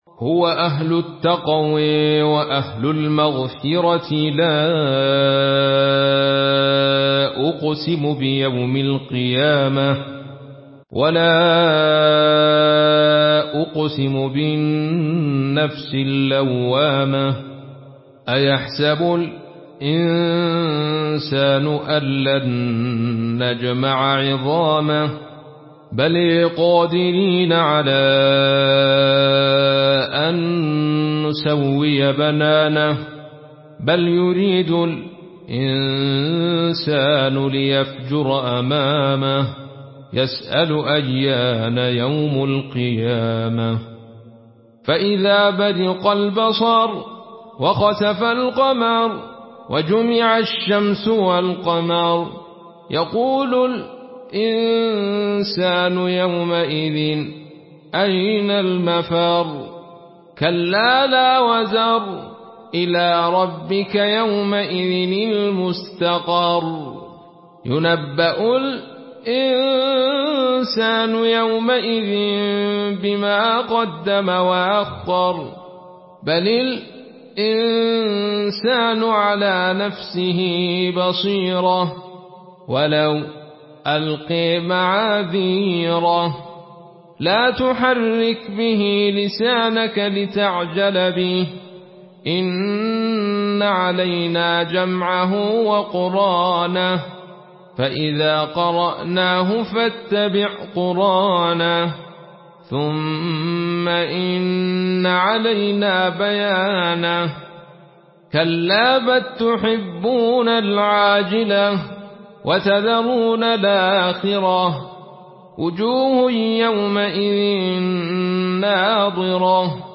Surah Al-Qiyamah MP3 by Abdul Rashid Sufi in Khalaf An Hamza narration.
Murattal Khalaf An Hamza